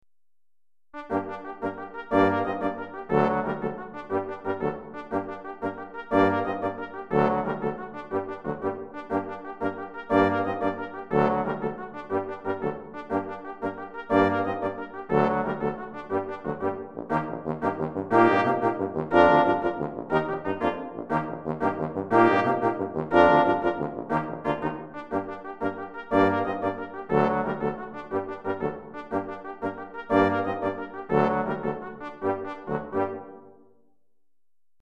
Trompette Sib, Cor, Trombone et Tuba